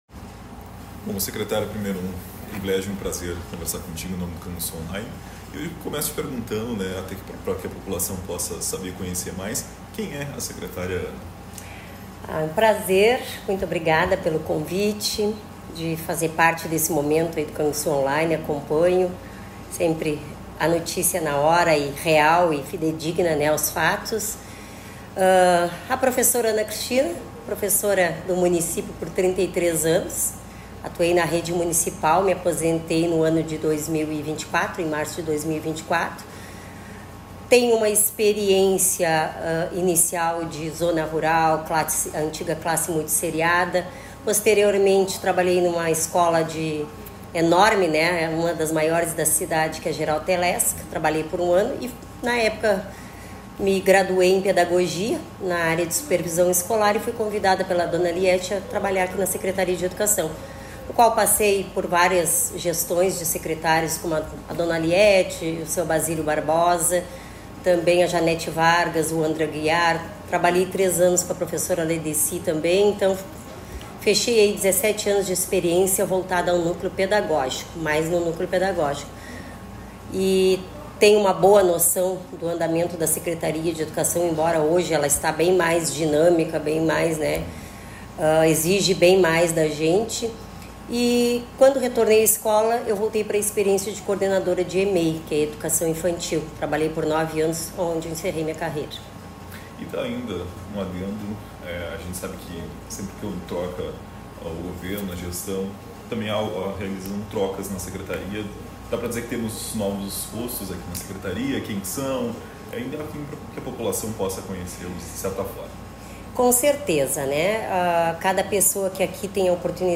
Em entrevista exclusiva ao Canguçu Online, secretária de Educação detalha o preparo para a volta às aulas, a contratação emergencial de profissionais e as prioridades de sua gestão iniciada em janeiro.
Entrevista.mp3